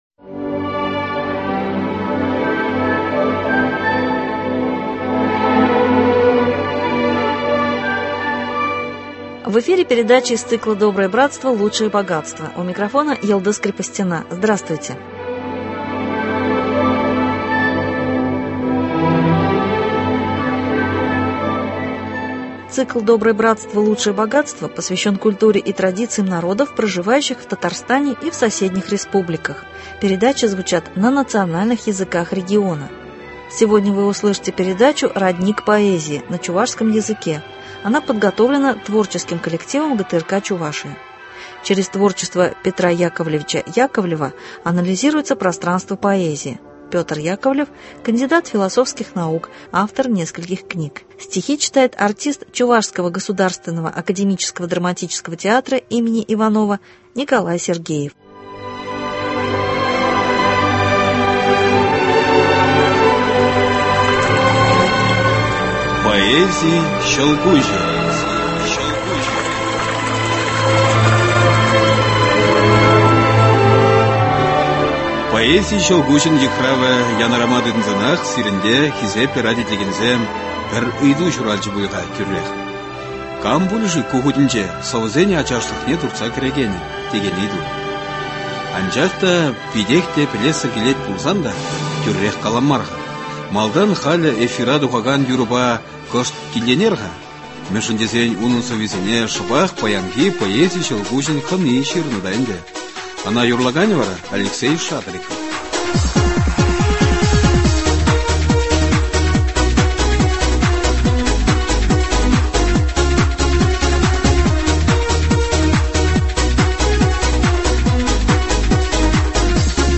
Литературная программа из фондов радио Чувашии.